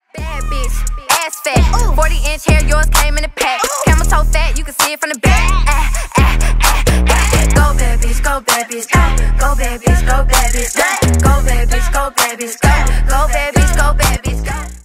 Рэп и Хип Хоп
громкие # клубные